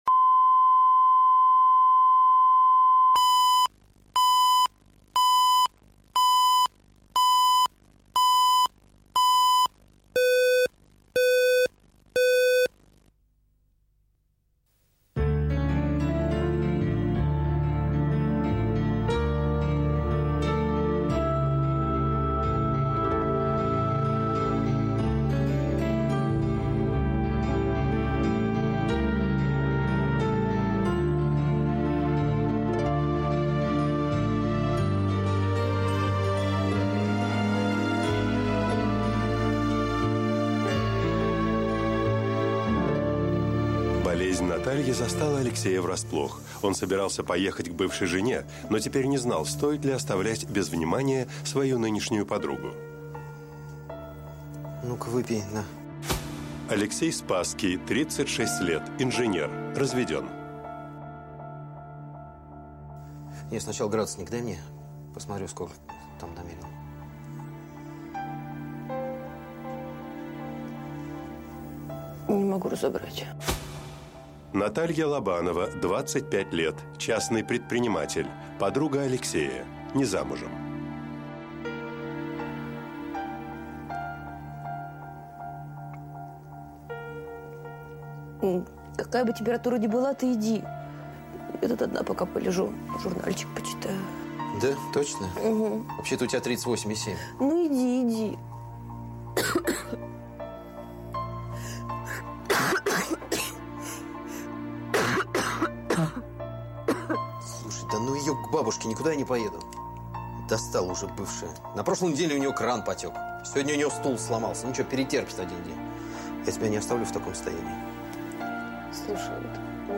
Аудиокнига Две жены | Библиотека аудиокниг